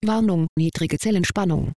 Zellenspannung.wav